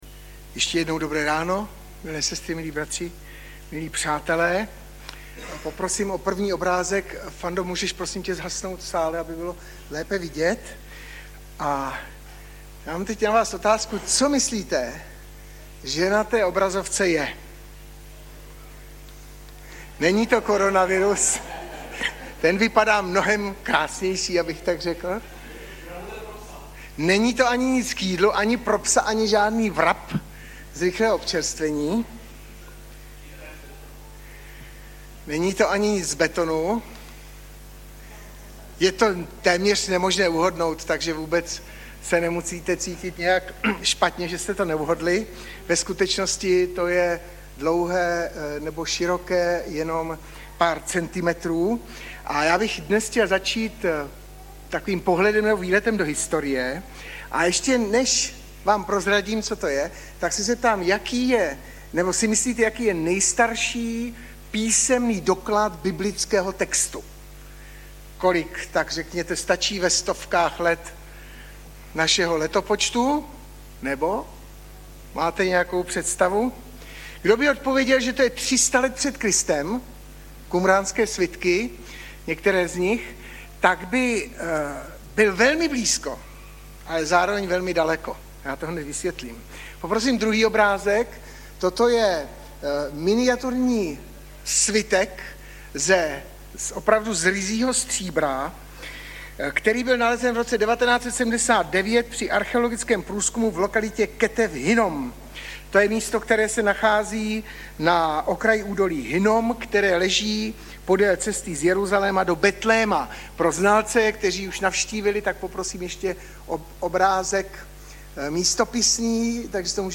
Webové stránky Sboru Bratrské jednoty v Litoměřicích.
Audiozáznam kázání si můžete také uložit do PC na tomto odkazu.